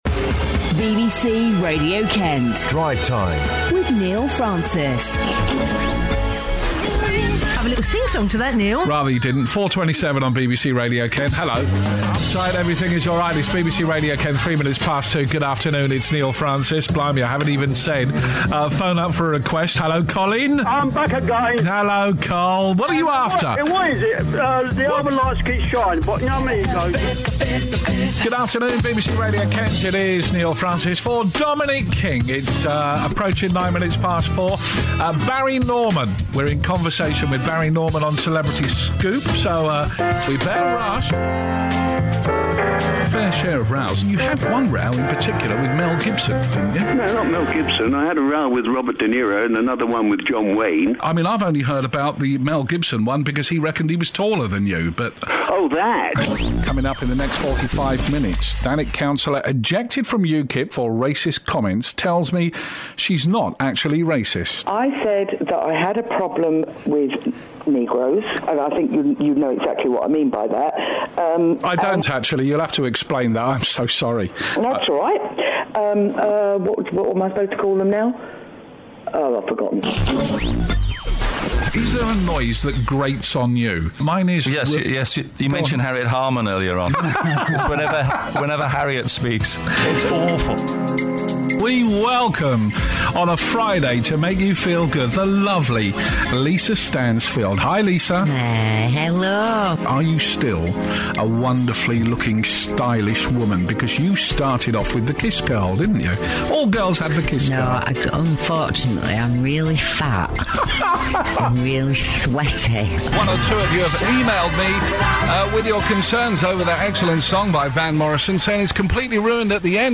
3_station_showreel.mp3